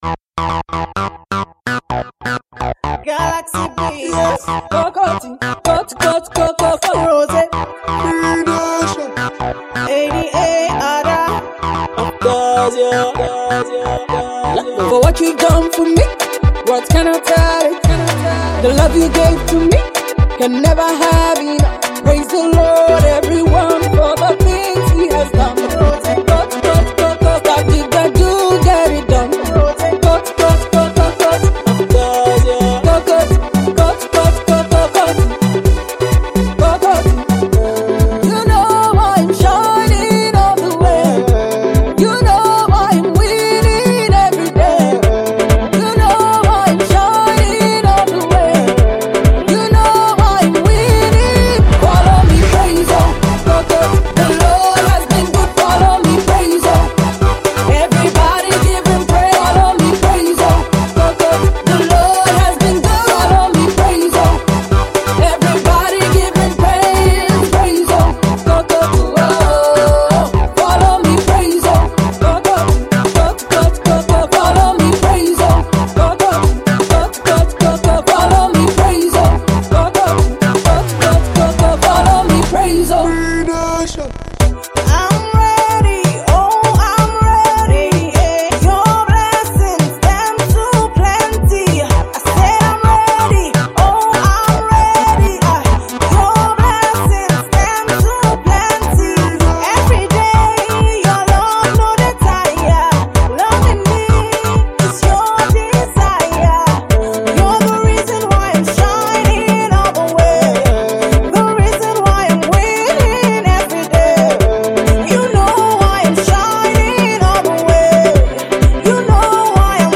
dance hall song with a pop feel